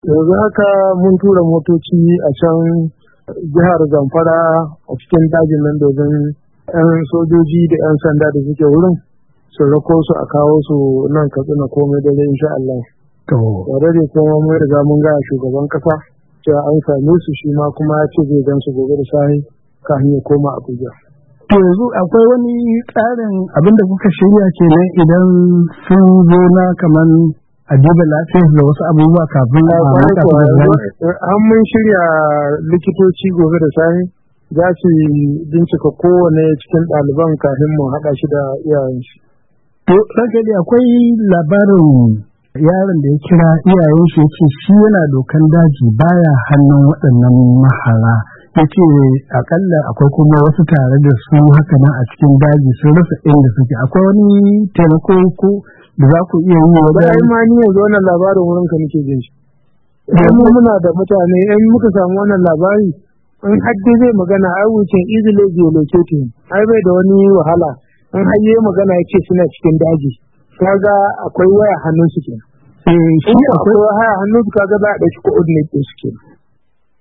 Hira da gwamna Aminu Bello Masari kan ceto daliban Kankara:1:30"